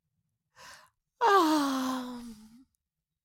Cartoon Little Child, Voice, Yawn 2 Sound Effect Download | Gfx Sounds
Cartoon-little-child-voice-yawn-2.mp3